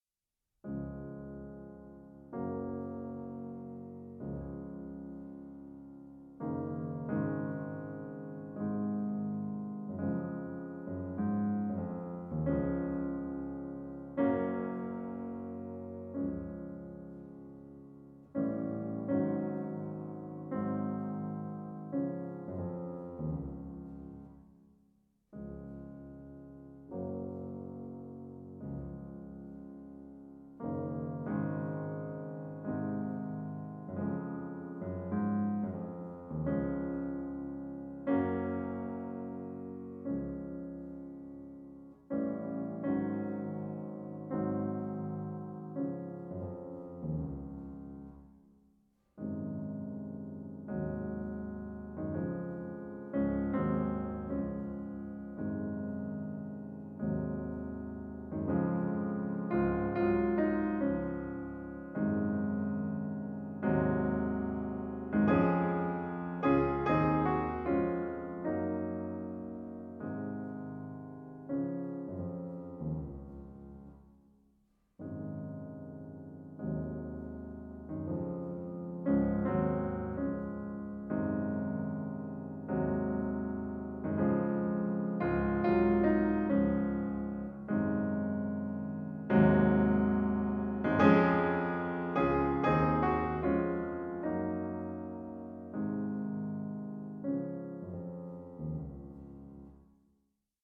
a warm, full-sounding bass voice and a formidable technique.
PIANO MUSIC